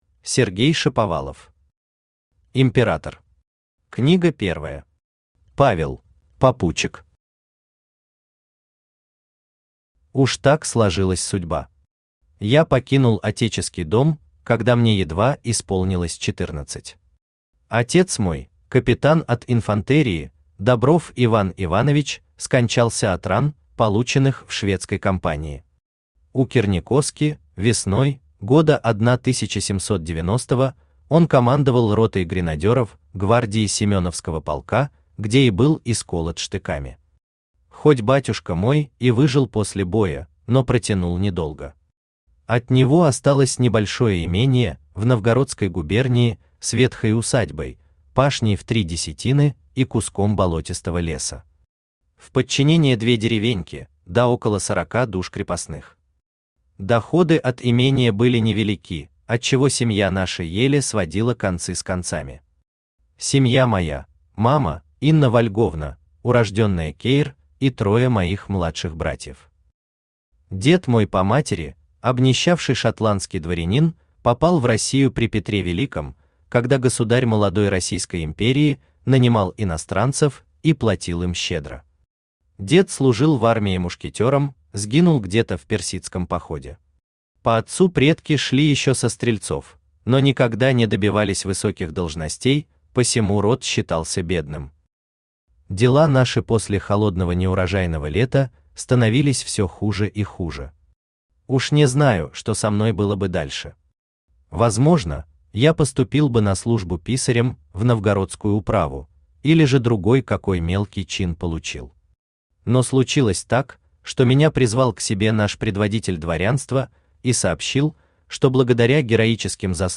Аудиокнига Император. Книга первая. Павел | Библиотека аудиокниг
Павел Автор Сергей Анатольевич Шаповалов Читает аудиокнигу Авточтец ЛитРес.